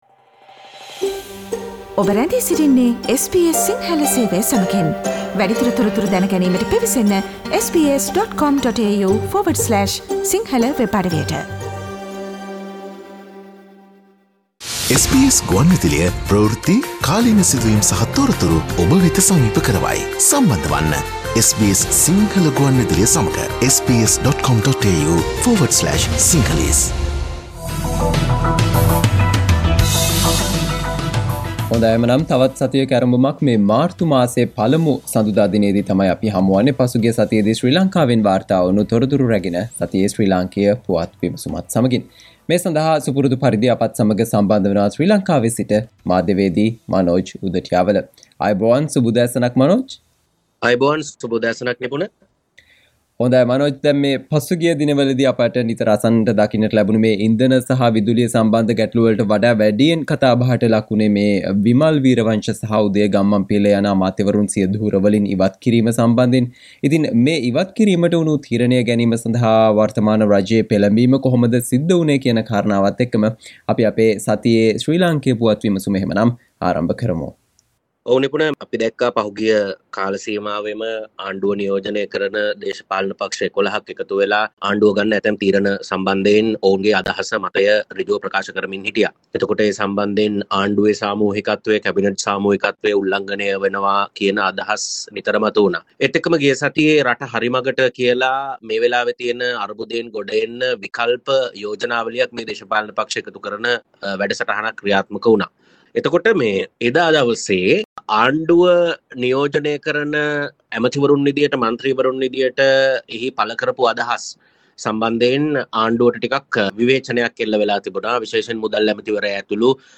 ශ්‍රී ලාංකීය පුවත්